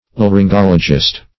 Laryngologist \Lar`yn*gol"o*gist\